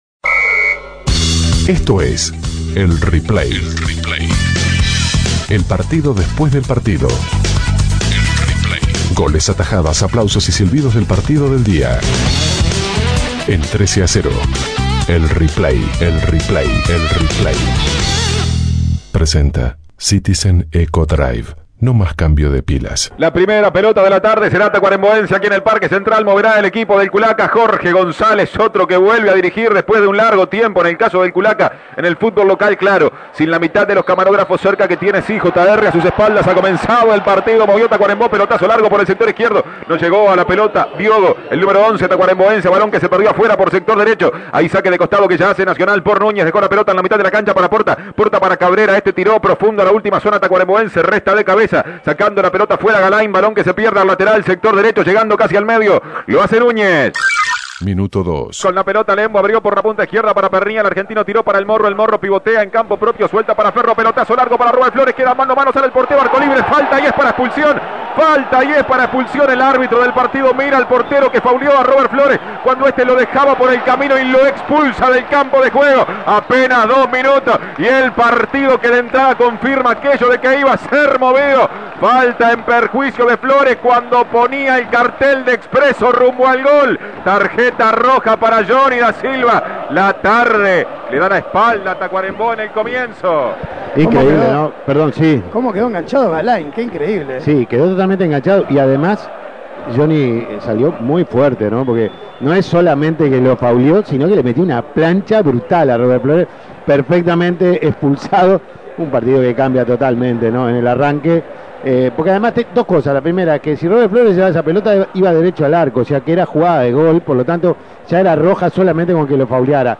Goles y comentarios